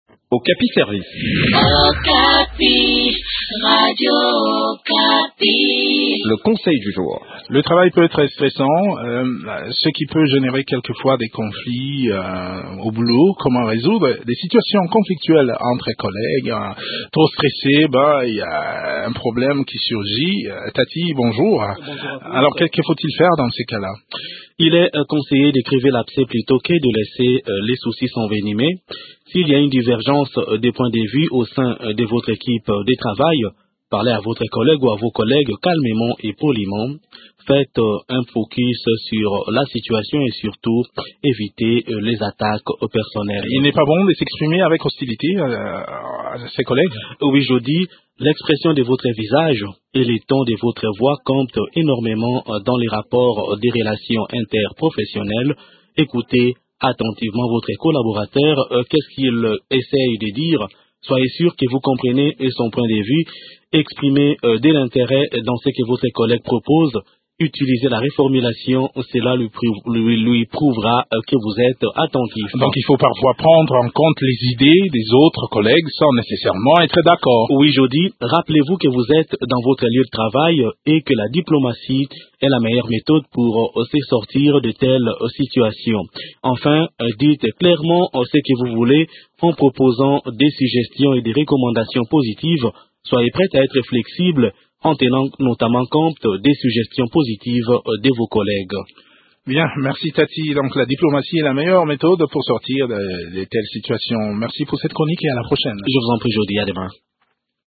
Découvrez des astuces dans cette chronique